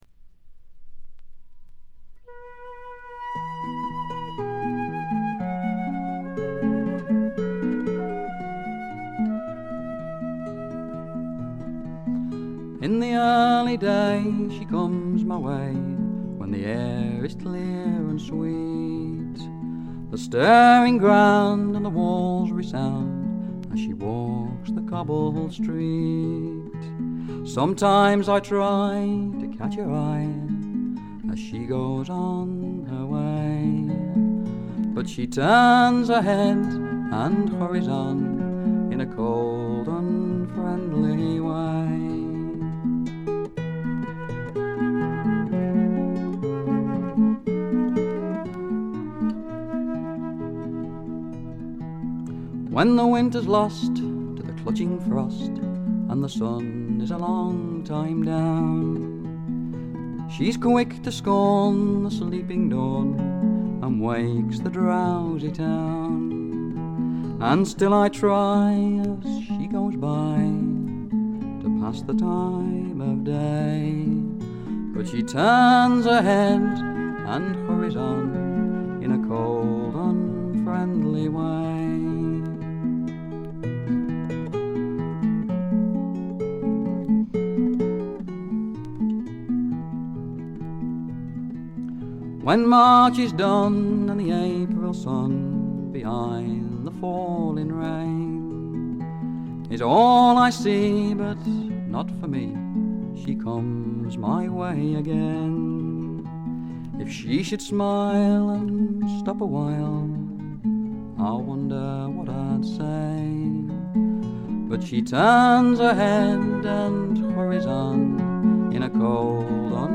試聴曲は現品からの取り込み音源です。
Flute